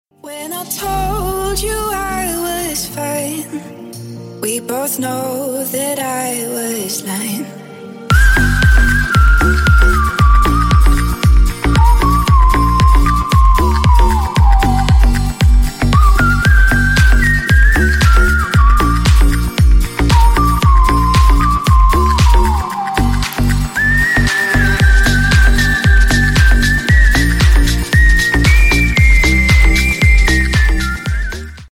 # Клубные Рингтоны
# Танцевальные Рингтоны